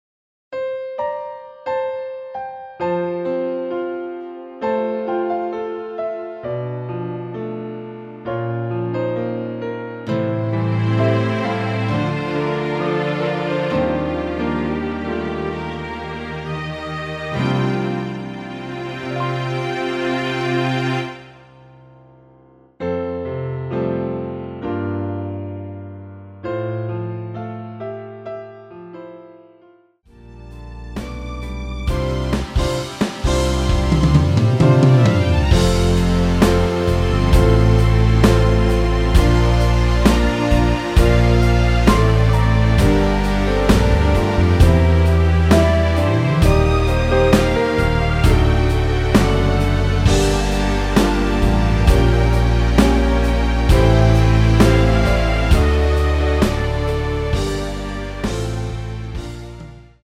(-2)내린 MR
◈ 곡명 옆 (-1)은 반음 내림, (+1)은 반음 올림 입니다.
앞부분30초, 뒷부분30초씩 편집해서 올려 드리고 있습니다.
중간에 음이 끈어지고 다시 나오는 이유는